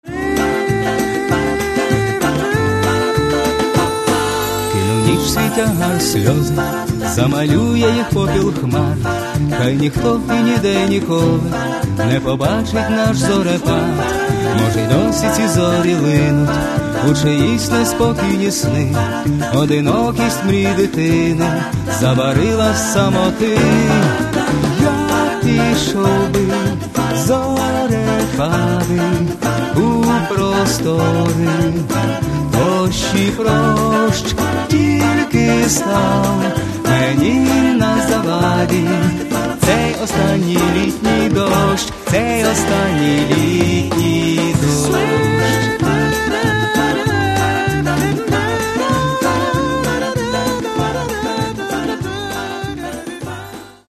Каталог -> Джаз и около -> Прочее близкое